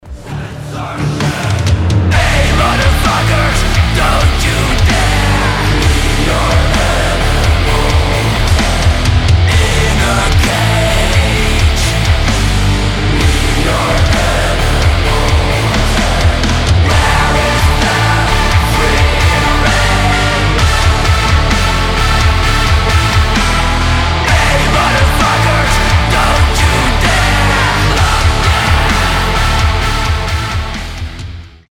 громкие
мощные
брутальные
злые
Industrial metal